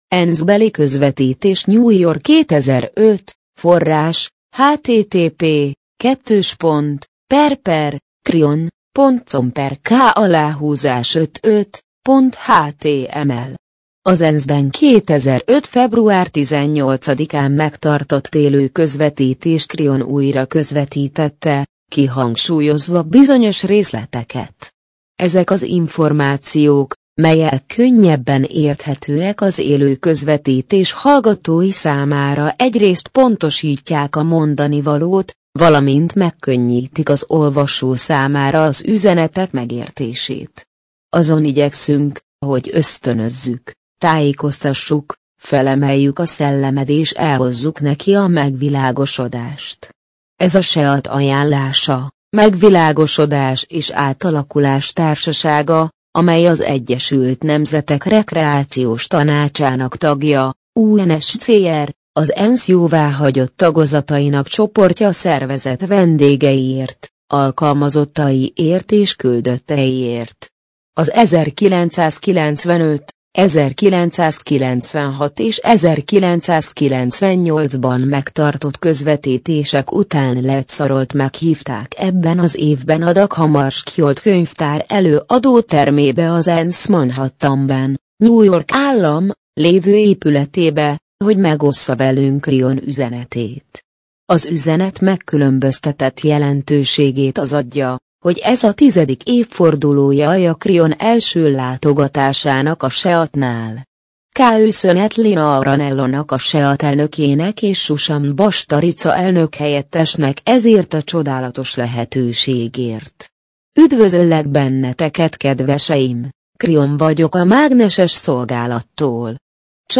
MP3 gépi felolvasás